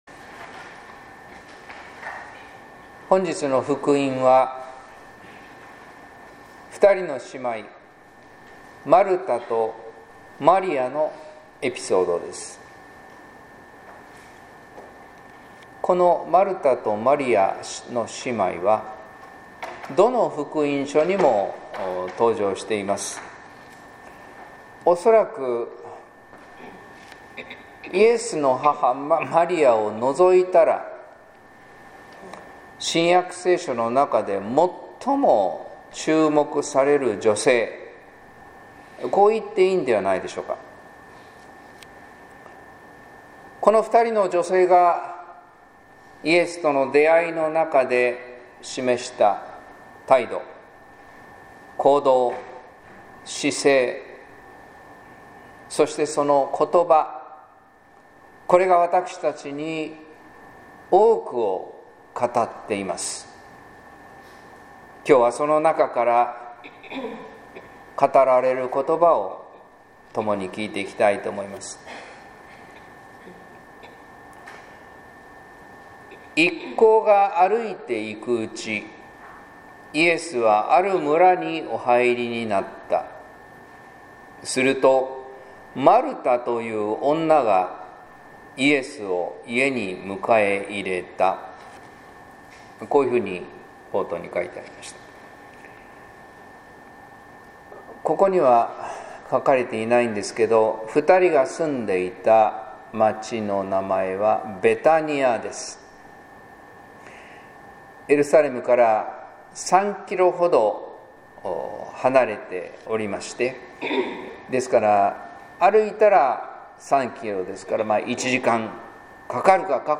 説教「ただ一つ必要なこと」（音声版）